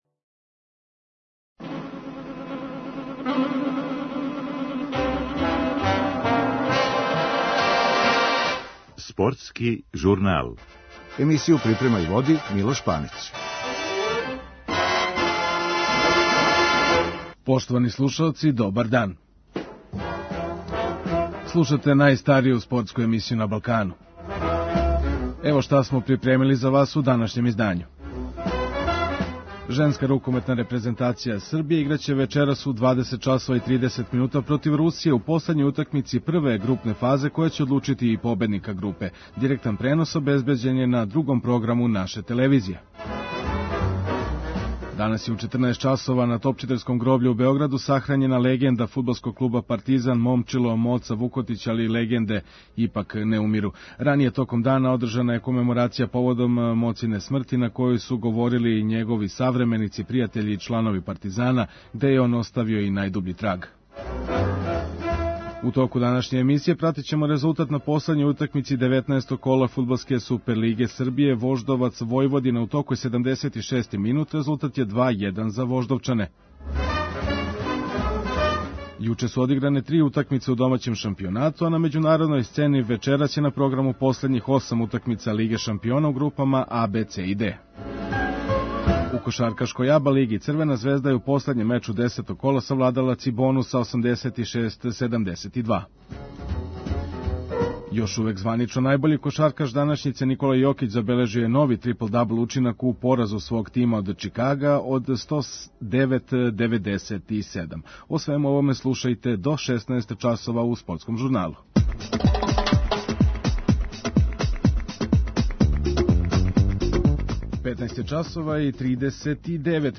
Никола Јокић је говорио за РТС, после пораза у Чикагу.